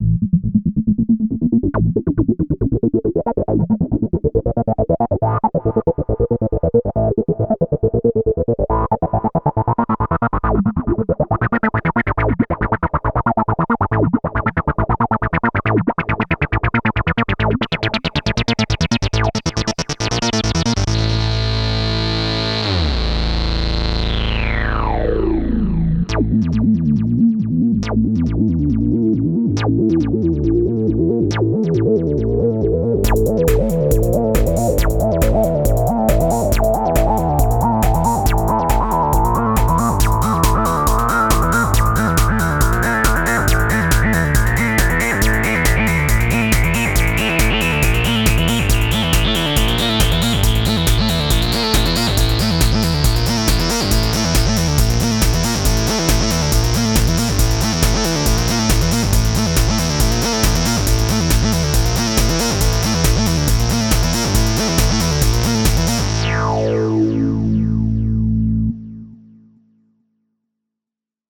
And here the first demo - MBSID in TB303 mode, controlled via MIDI (internal
sequencer not used) - filter/envelope parameters tweaked in realtime
IMHO the filter sounds very good, especially on filter sweeps it can be noticed
that the volume doesn't get lower even when resonance is close to self-oscillation.
Yes, it's just the TB303 mode - not the typical TB303 sound.